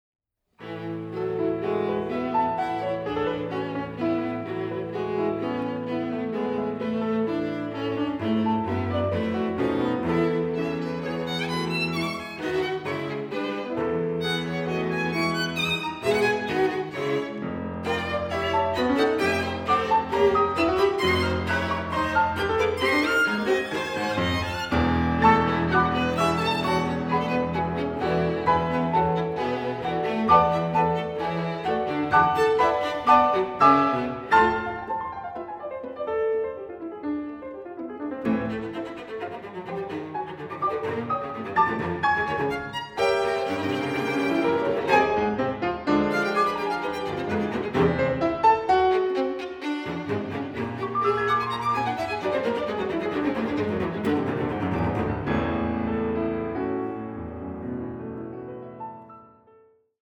I. Vivace ma non troppo